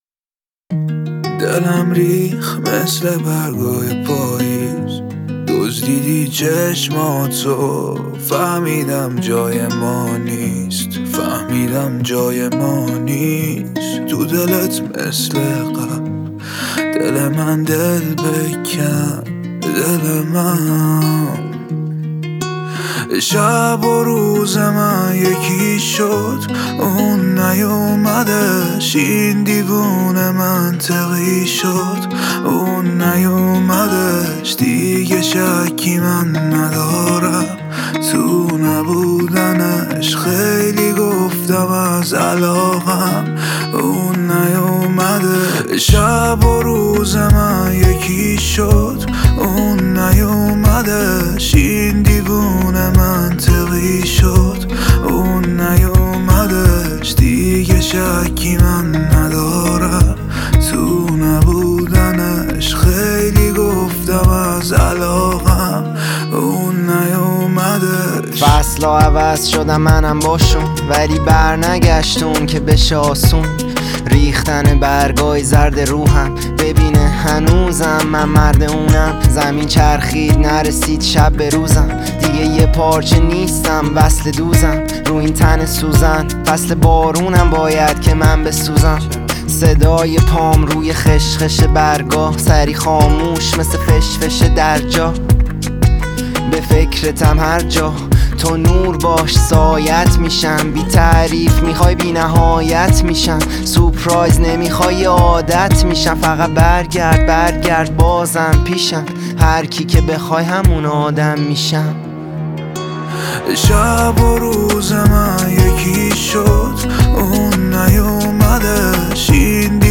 آهنگ رپ جدید آهنگ احساسی